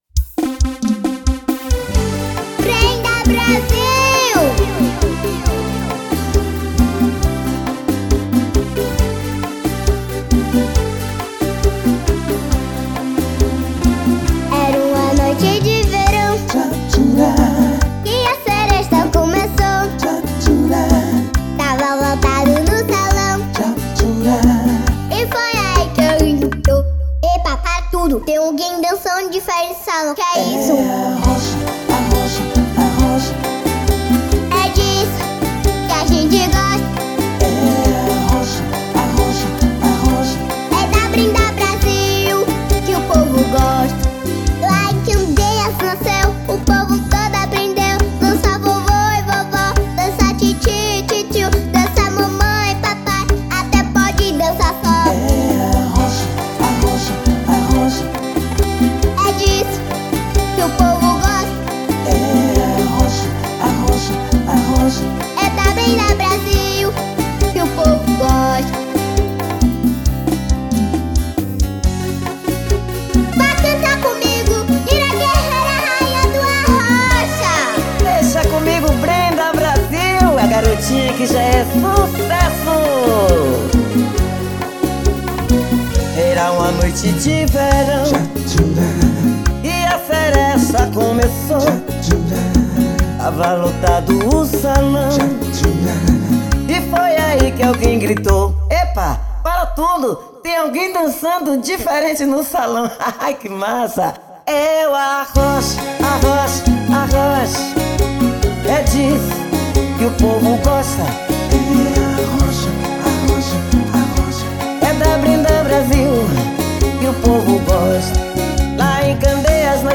EstiloAlternativo
Cidade/EstadoSalvador / BA